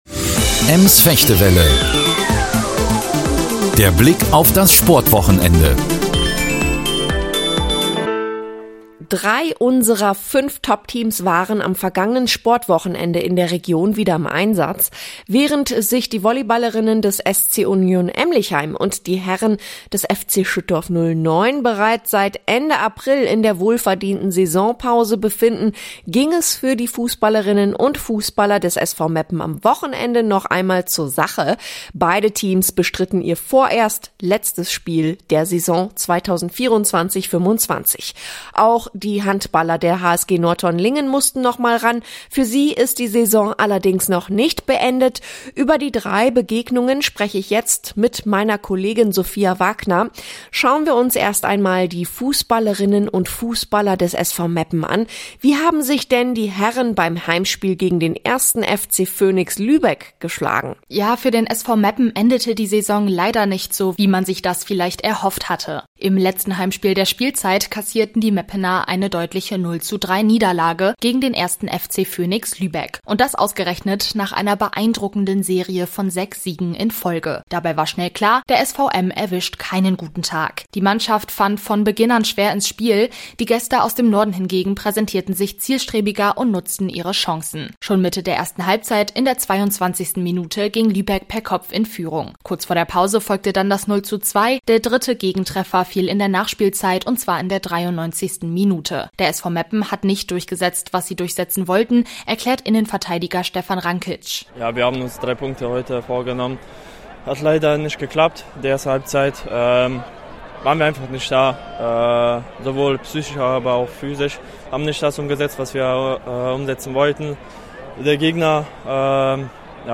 Sportrückblick: Saisonfinale für Meppens Fußballteams – Handballer noch im Spiel